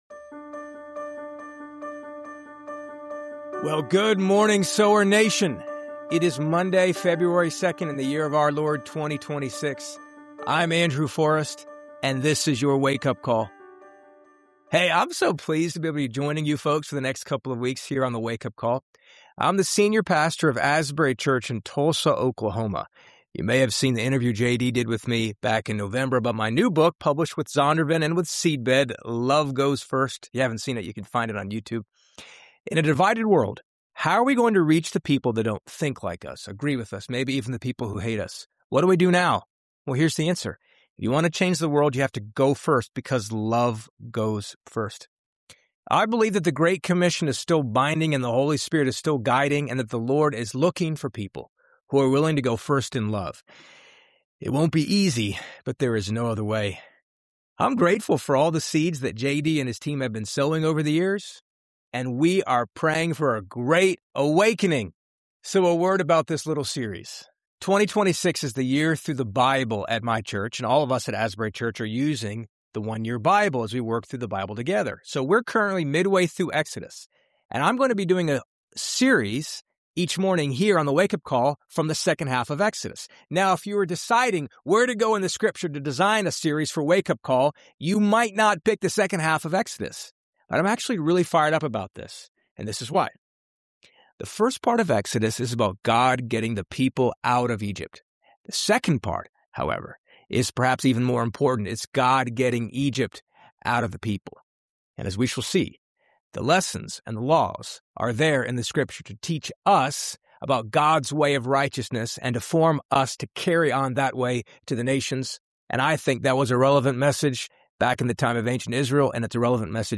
Stay tuned for stirring journal prompts to help you apply these truths to your own life, and be uplifted by a beautiful rendition of the hymn “How Deep the Father’s Love for Us” that will remind you of the depth of God’s grace.